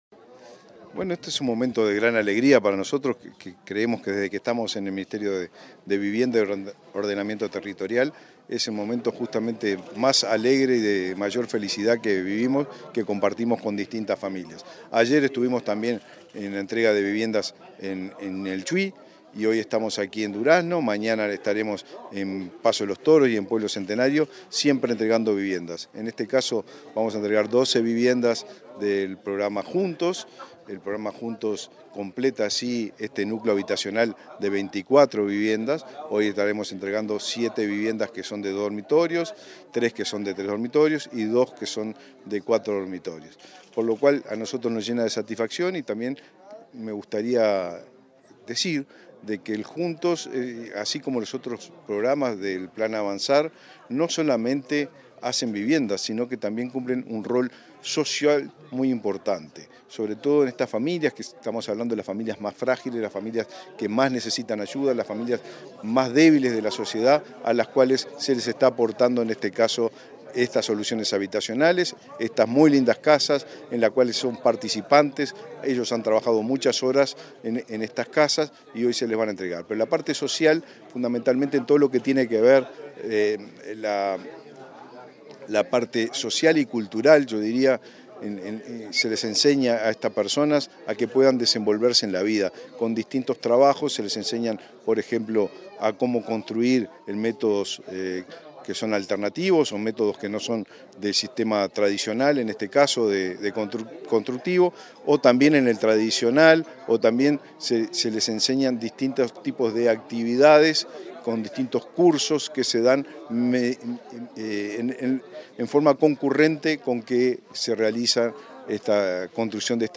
Entrevista al ministro de Vivienda y Ordenamiento Territorial, Raúl Lozano